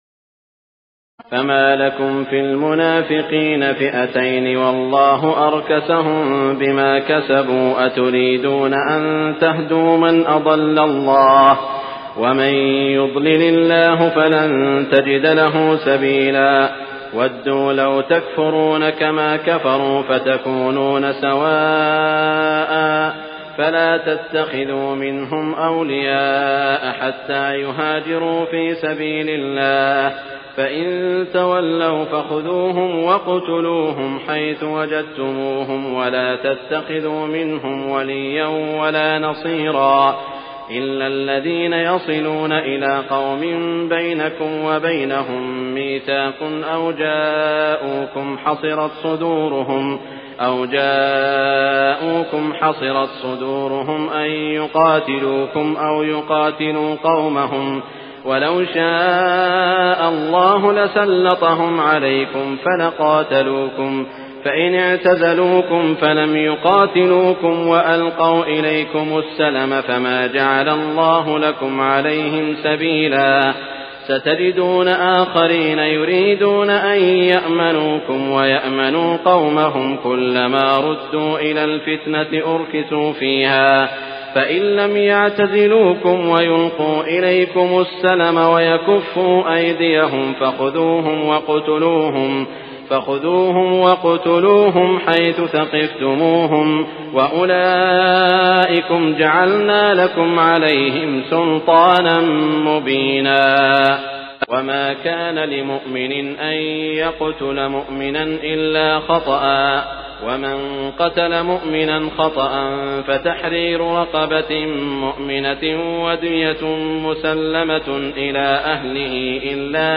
تراويح الليلة السادسة رمضان 1418هـ من سورة النساء (88-159) Taraweeh 6 st night Ramadan 1418H from Surah An-Nisaa > تراويح الحرم المكي عام 1418 🕋 > التراويح - تلاوات الحرمين